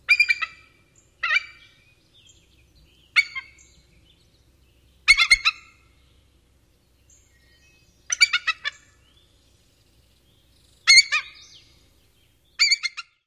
白头海雕叫声